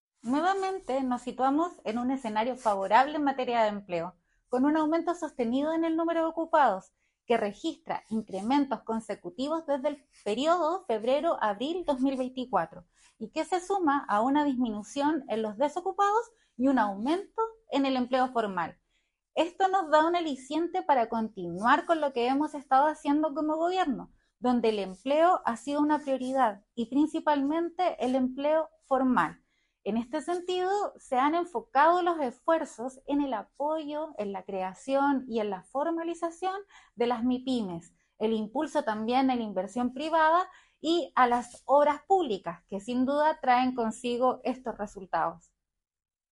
Al respecto la Seremi del Trabajo, Monserrat Castro, destacó la nueva disminución de la tasa de desocupación y comentó que